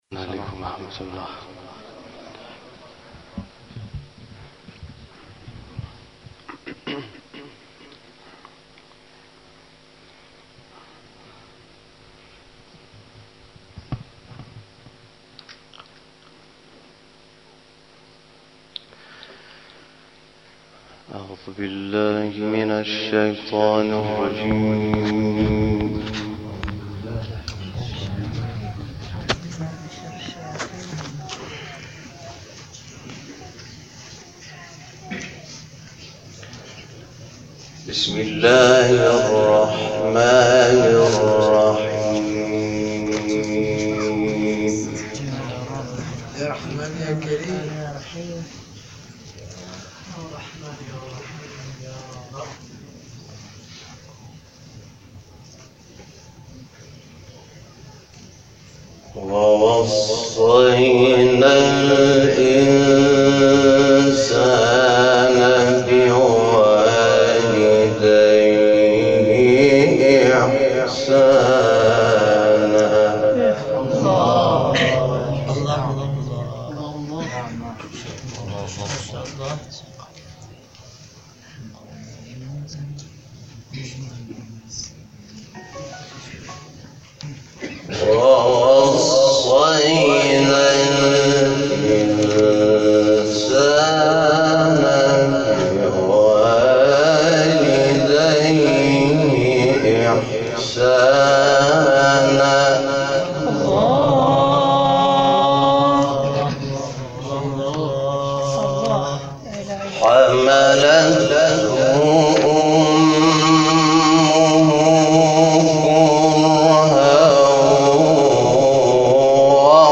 تلاوت «شاکرنژاد» در حسینیه بنی‌الزهرا(س) + تصاویر
گروه شبکه اجتماعی: تلاوت شب اول و دوم حامد شاکرنژاد در حسینیه بنی الزهراء(س) را می‌شنوید.